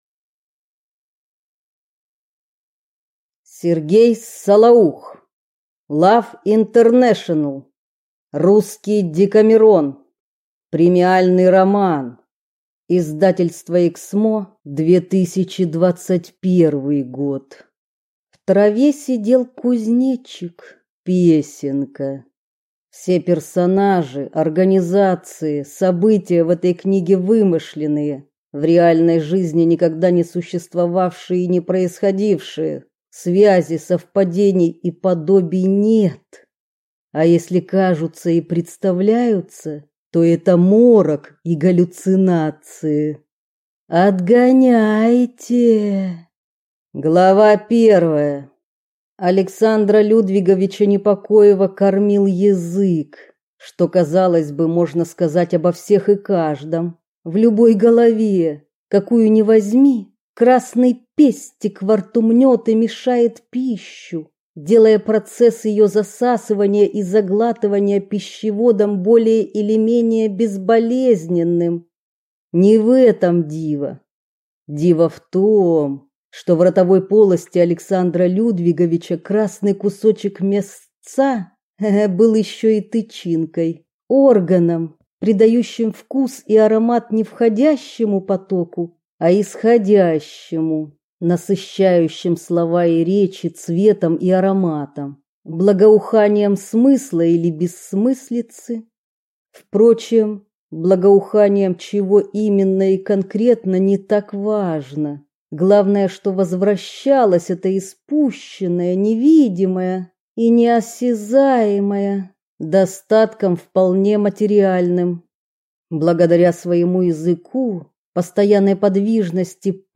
Аудиокнига Love International | Библиотека аудиокниг